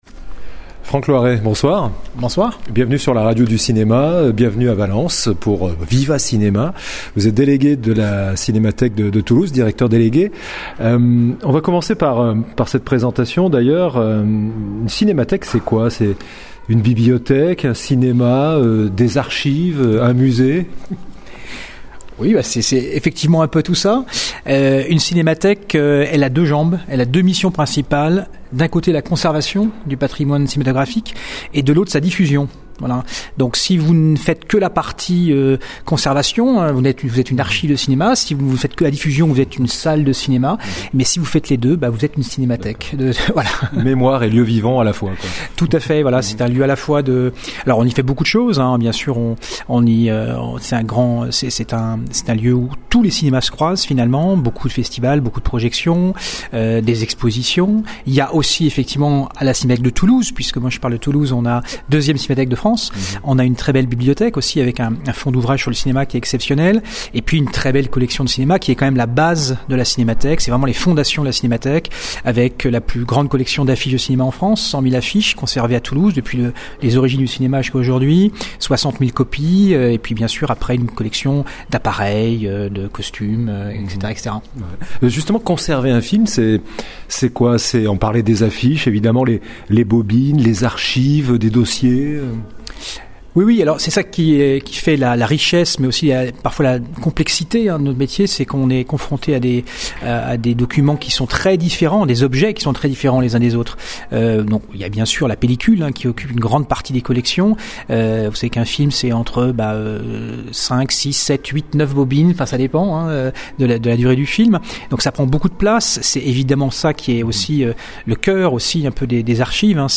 podcast cinéma avec interviews, analyses, critiques, chroniques et actualités du cinéma.